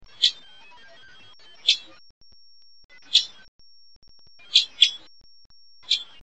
a. A hard kip or kip-kip (P).
A low, double tick-tick (R).
Has a dry quality. In northwestern California, it is characteristically doubled. When excited, may string these notes together into a rattle or unmusical trill.